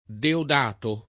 vai all'elenco alfabetico delle voci ingrandisci il carattere 100% rimpicciolisci il carattere stampa invia tramite posta elettronica codividi su Facebook Diodato [ diod # to ] o Deodato [ deod # to ] pers. m. stor.